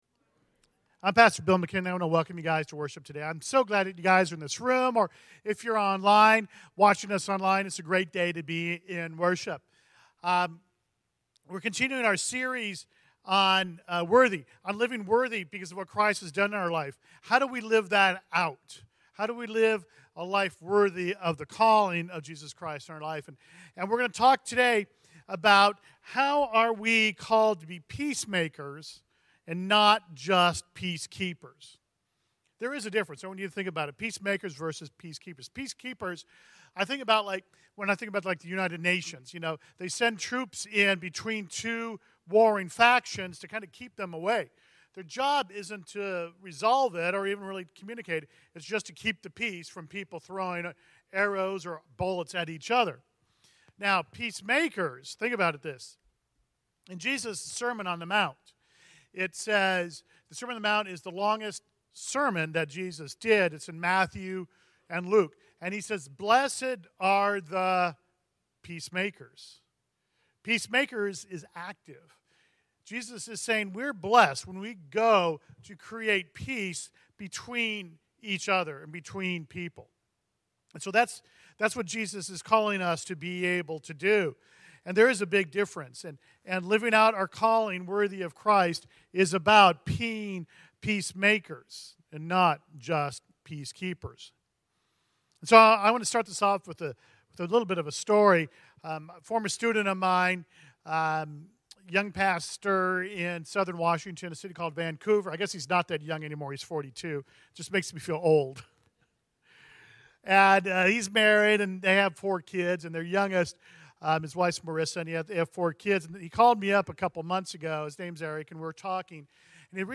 CTK-October-30-Full-Sermon-1.mp3